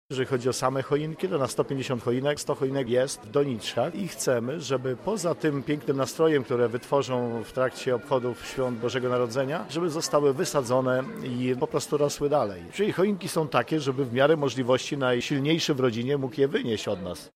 O szczegółach mówi wicemarszałek województwa lubelskiego, Grzegorz Kapusta: